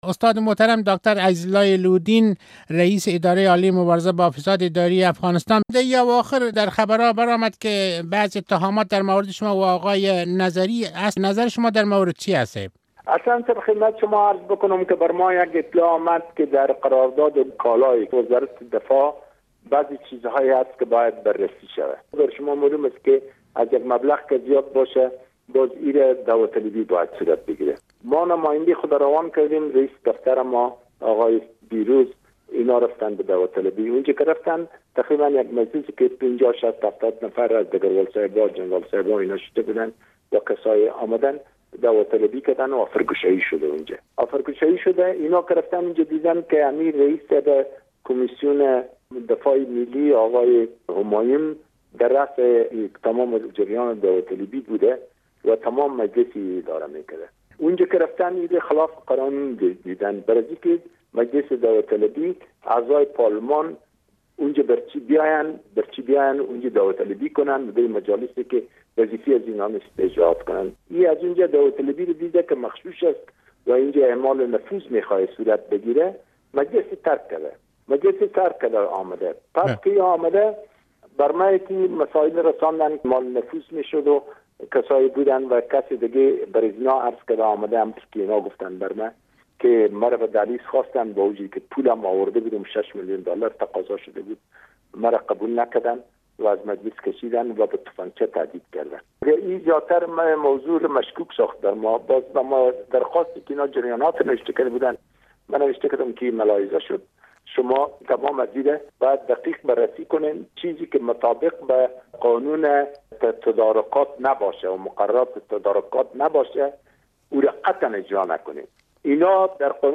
مصاحبه با داکترعزیزالله لودین در مورد اتهام واردهء فساد بر وی